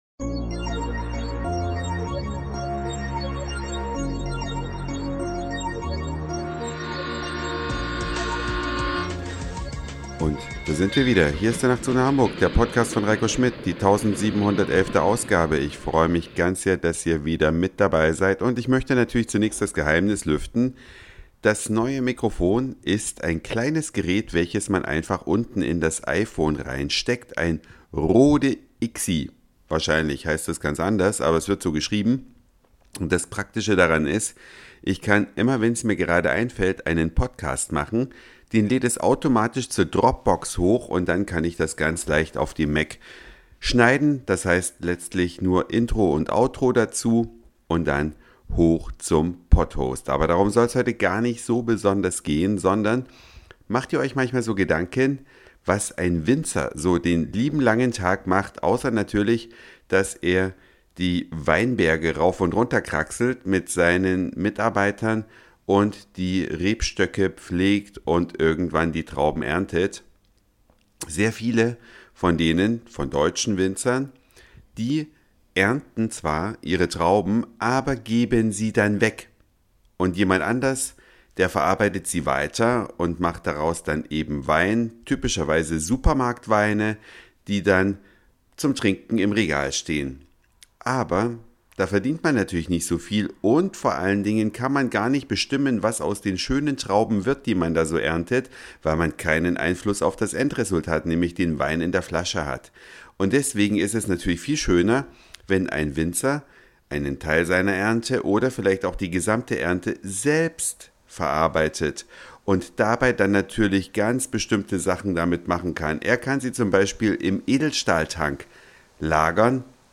Der Winzer stellt seine Weine persönlich vor NnH bei iTunes